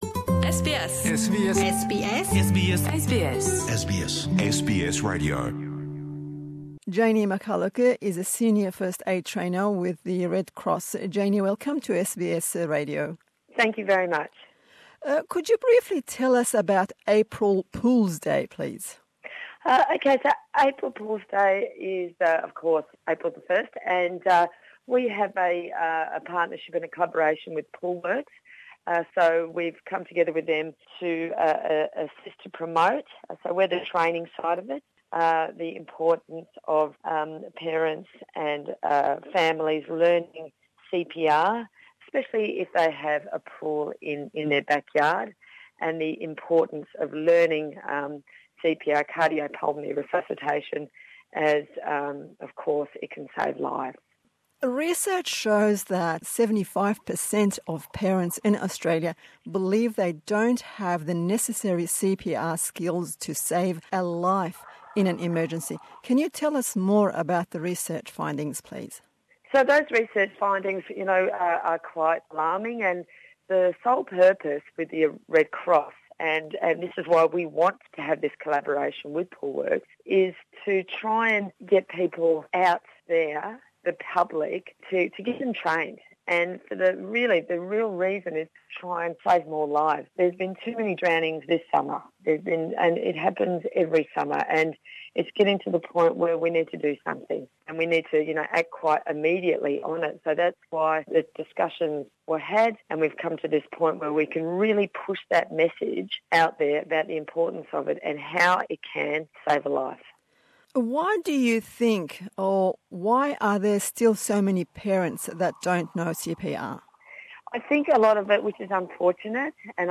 Ev hevpeyvîna bi zimanî Îngilî li ser girîngiya CPR ango zanîna rizgarkirina mirovan ji tenegenefesiyê di dema tehlûkeya di ajiniyê de.